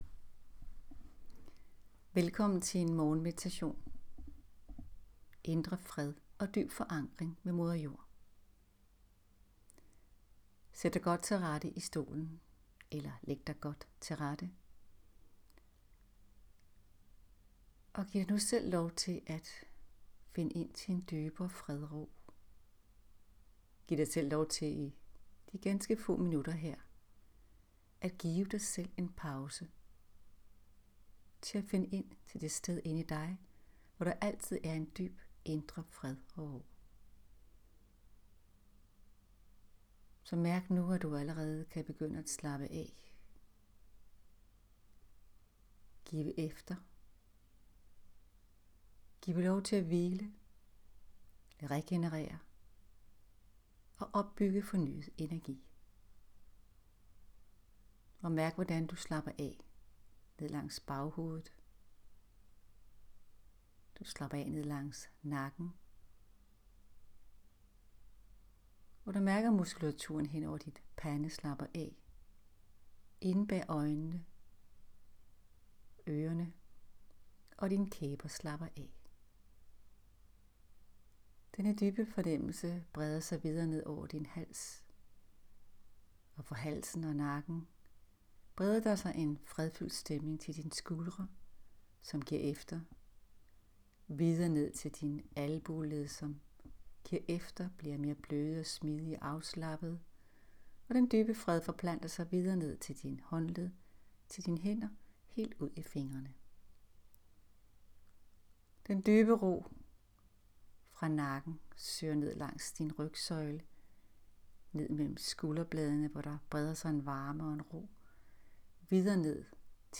Morgenmeditation - Indre fred og forankring.m4a